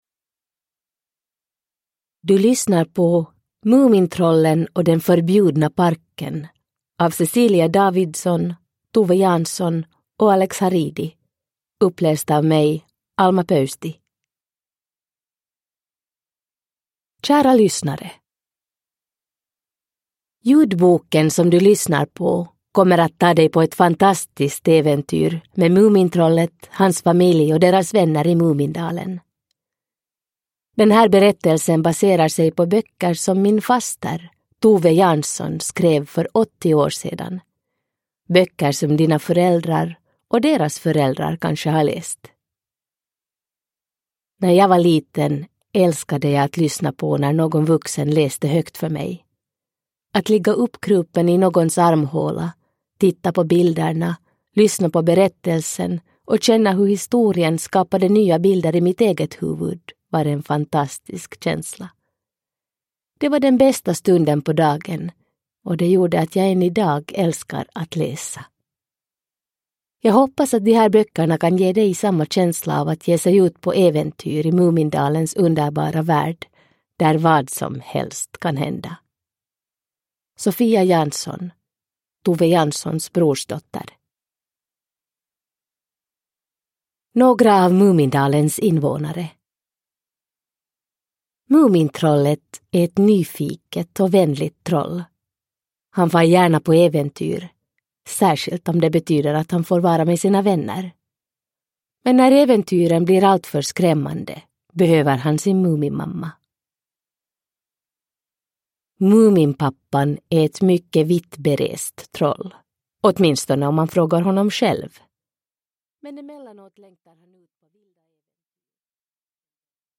Uppläsare: Alma Pöysti
Ljudbok